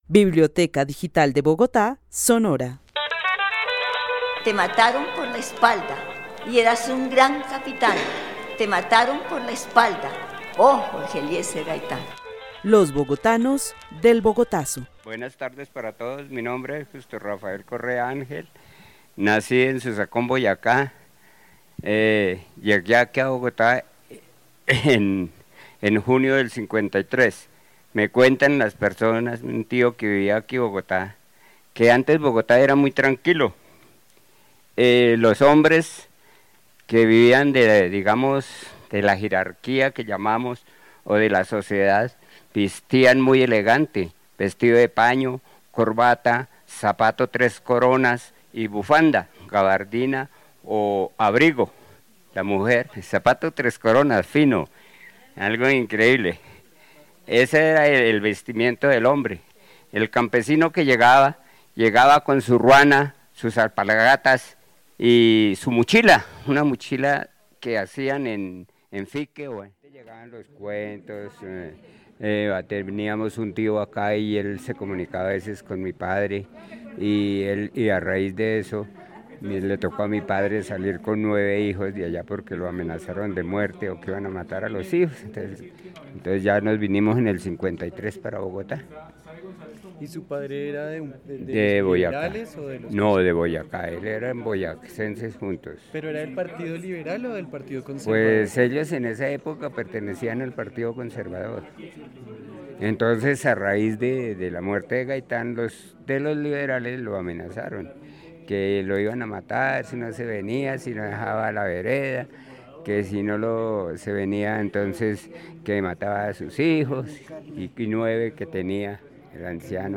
Narración oral de las primeras impresiones de su vida frente a la Bogotá de la década de 1950.
El testimonio fue grabado en el marco de la actividad "Los bogotanos del Bogotazo" con el club de adultos mayores de la Biblioteca El Tunal.